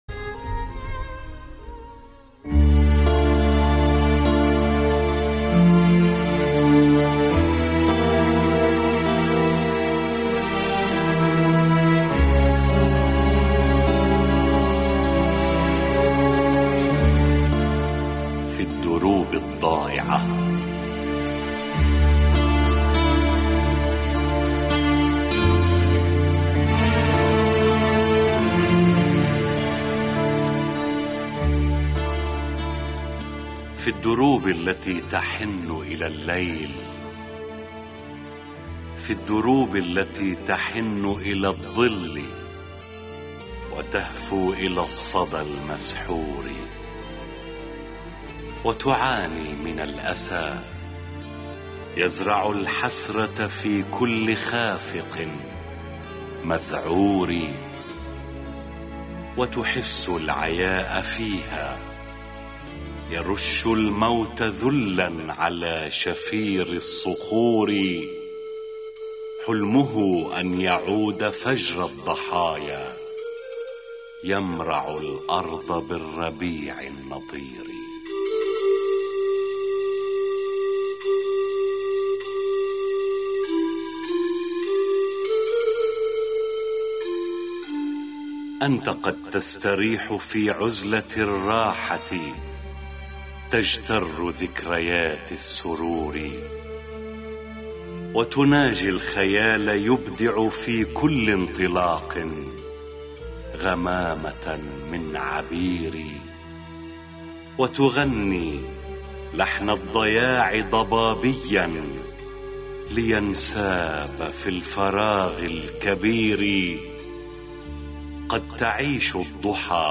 شعر
- استماع أو تحميل الملف الصوتي بصوت اذاعي